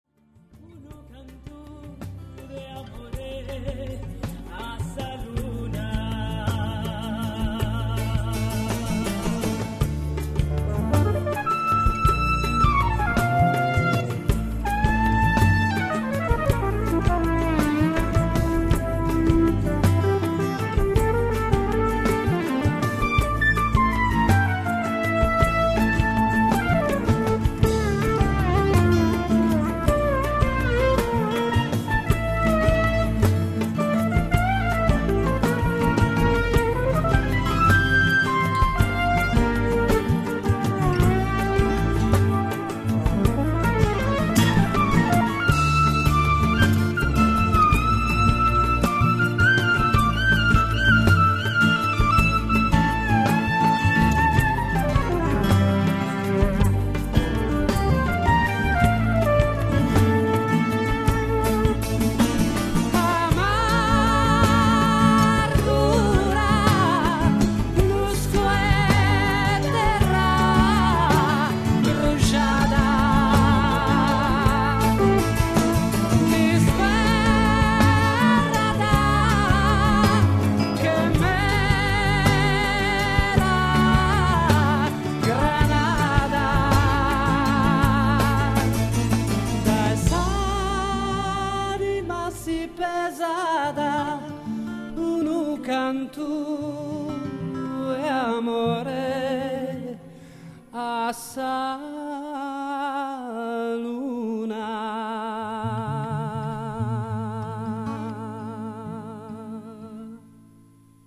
From the same song as above, a synth solo from the AN1x: